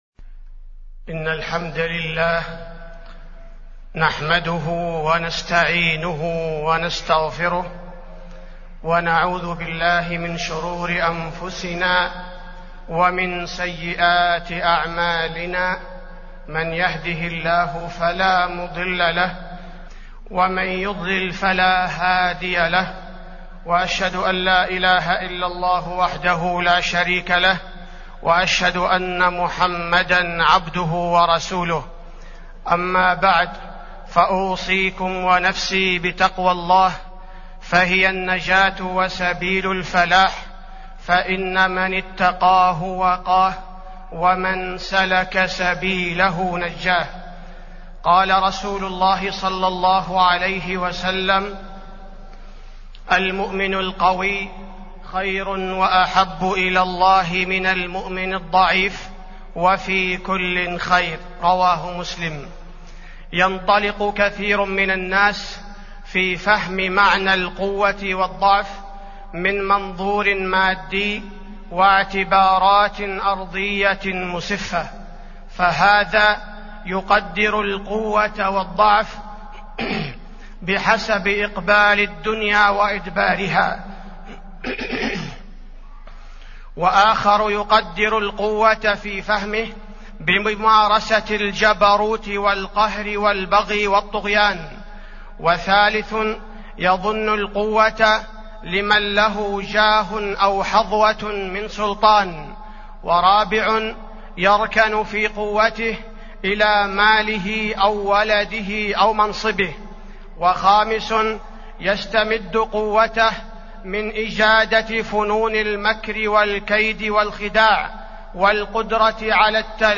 تاريخ النشر ٢٥ جمادى الآخرة ١٤٢٧ هـ المكان: المسجد النبوي الشيخ: فضيلة الشيخ عبدالباري الثبيتي فضيلة الشيخ عبدالباري الثبيتي المؤمن القوي خير من المؤمن الضعيف